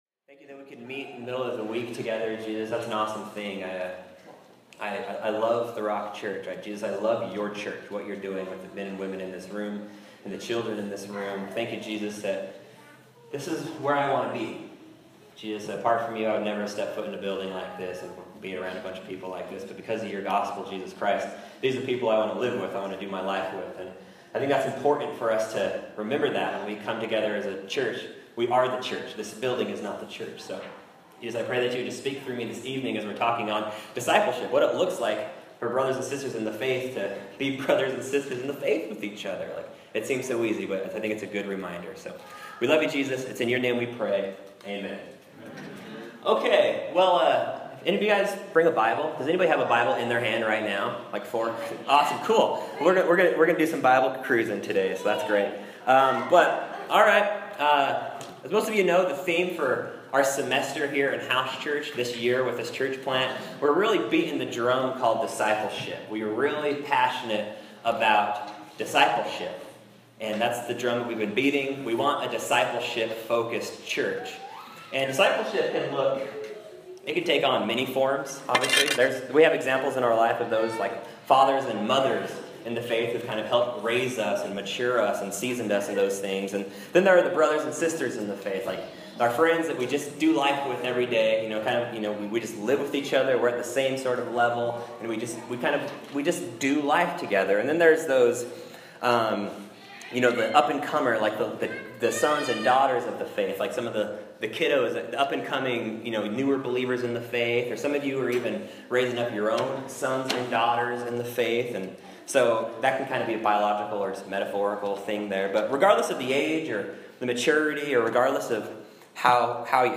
Mid-week messages from the Rock Church West House Church Gatherings.